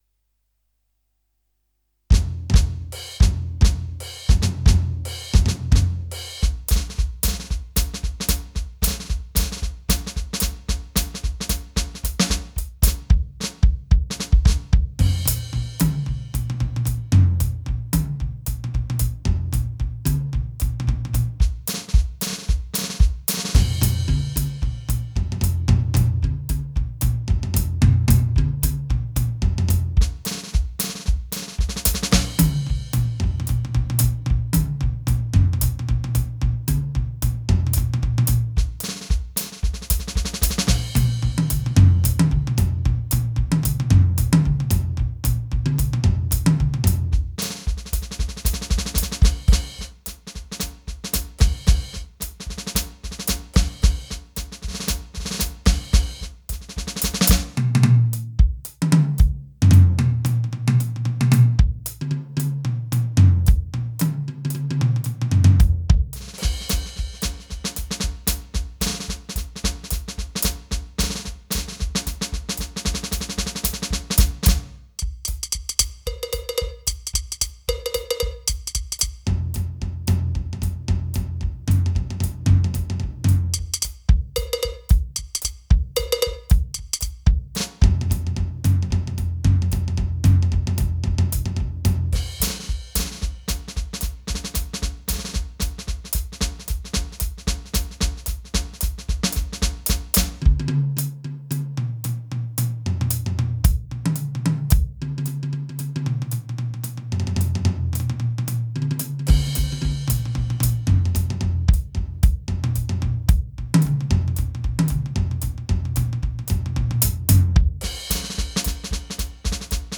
volledig gebaseerd op de oude stijl jazz
2e Aantal Stemmen : 4 PDF